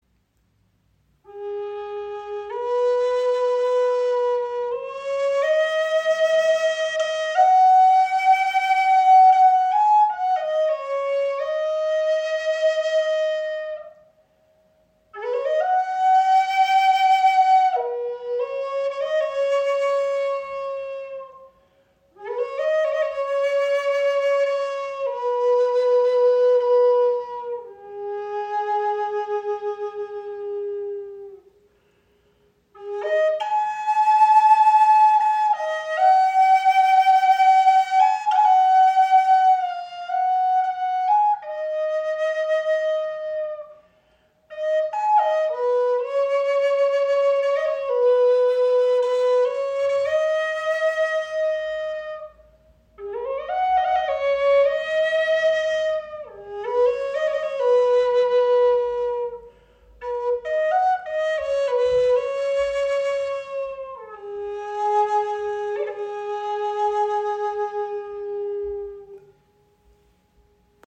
Gebetsflöte in G# – 440 Hz | Handgefertigt aus indischem Rosenholz • Raven Spirit
• Icon Handgefertigt aus indischem Rosenholz mit Canary Holz Windblock
Entdecke die handgefertigte Gebetsflöte in G# – 440 Hz aus indischem Rosenholz mit Canary Holz Windblock. Präzise gestimmt für klare, harmonische Töne – ideal für Meditation, Klangarbeit und spirituelle Praxis.